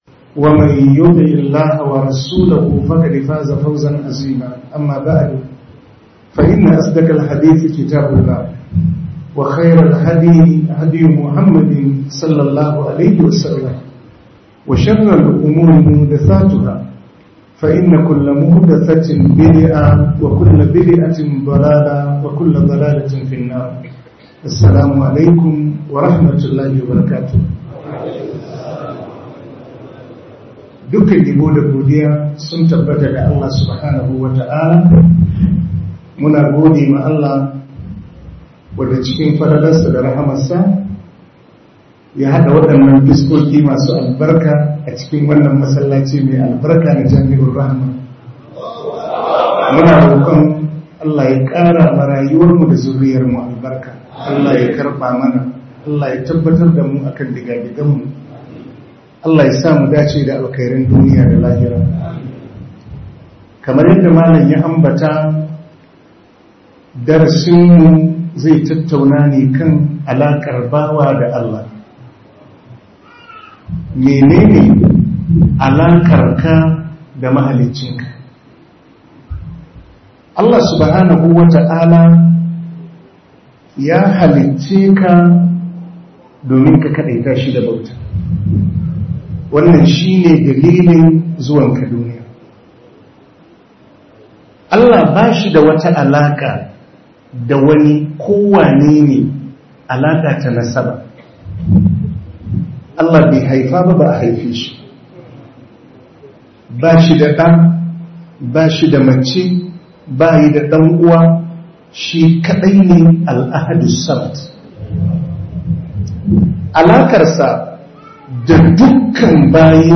Alaka Tsakanin Allah Da Bawa - MUHADARA